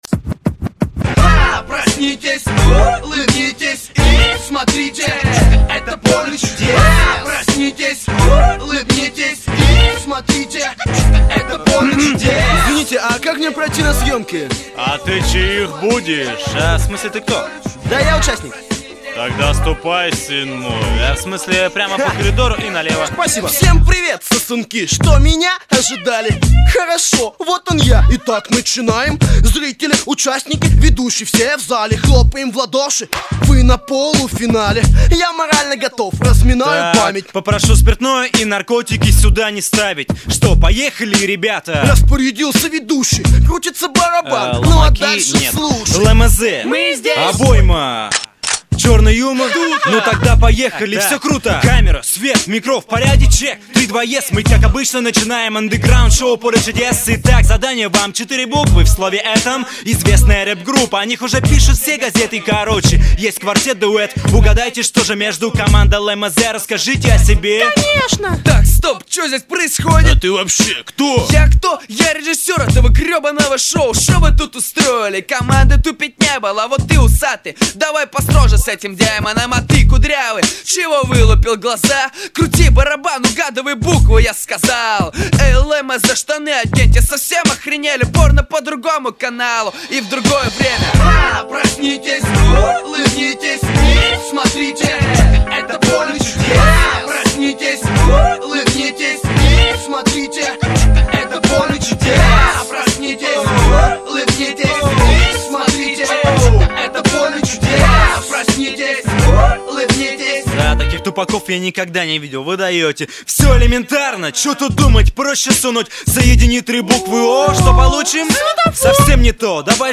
2005 Рэп Комментарии